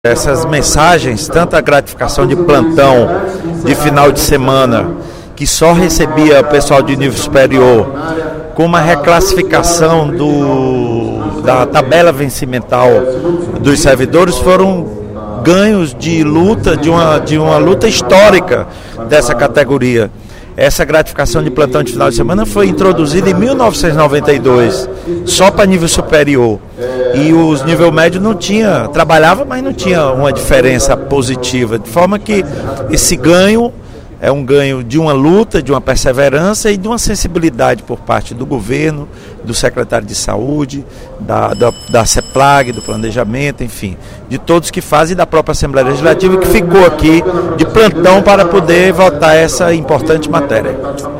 O deputado Lula Morais (PCdoB) avaliou, durante pronunciamento no primeiro expediente da Assembleia Legislativa nesta sexta-feira (28/12), a mensagem do Governo que concede benefícios aos servidores de nível médio da área de saúde.